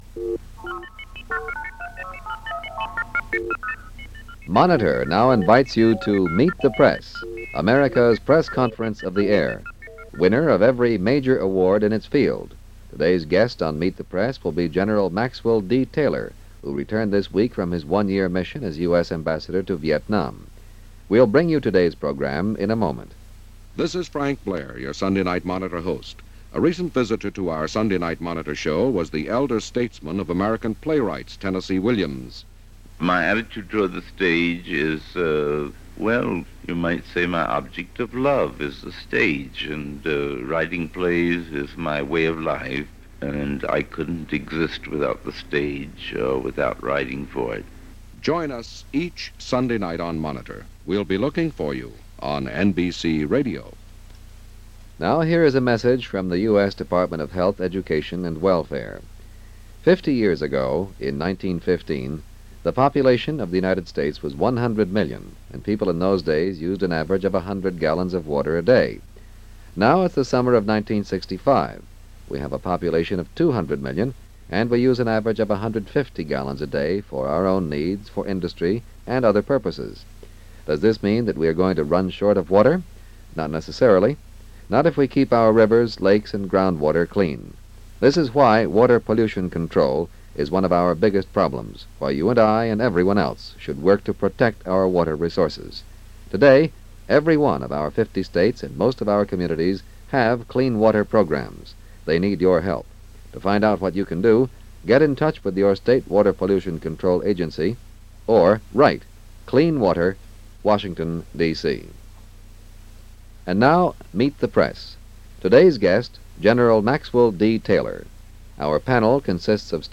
General Maxwell Taylor, returning to the U.S. after spending a year as our Ambassador to South Vietnam went before the panel on Meet The Press and told, in no uncertain terms, that this was going to be a long, protracted War and that we better get used to it.
As is evidenced by the testy exchange between Taylor of the correspondents. Taylor seemed unwilling to make any predictions, unable to give a timeframe and this was a cause for concern.
NBC-Meet-The-Press-Gen.-Maxwell-Taylor-Aug.-8-1965.mp3